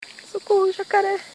Play, download and share Bim em Pânico original sound button!!!!
novo-toque-de-mensagem.mp3